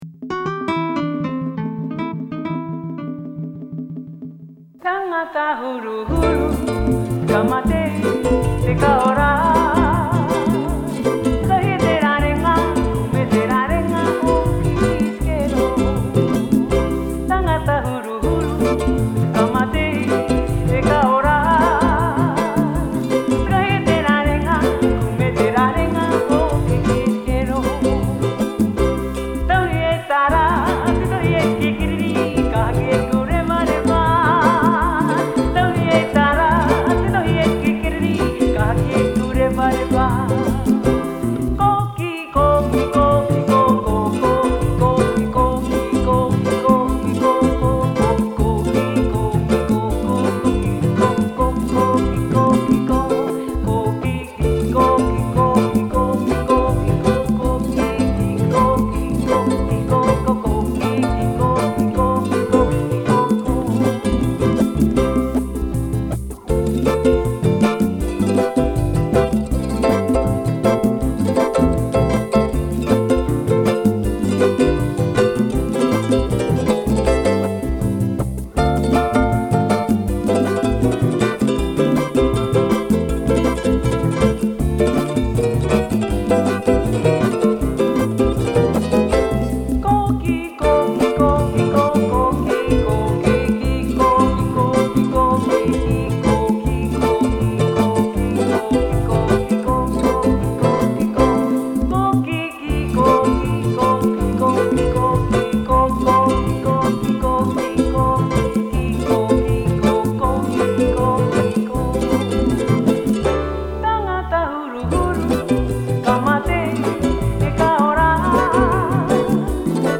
version chantée mp3